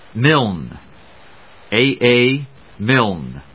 音節Milne 発音記号・読み方/míl(n)/発音を聞く